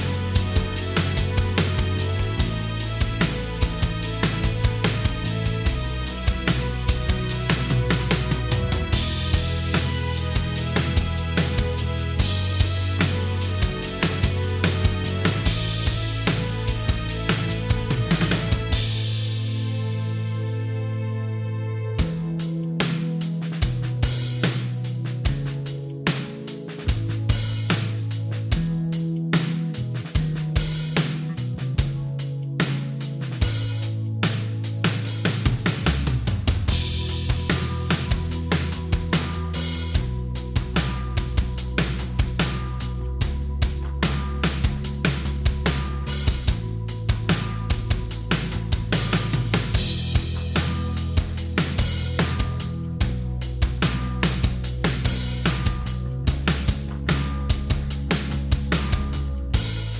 Minus Main Guitars For Guitarists 4:03 Buy £1.50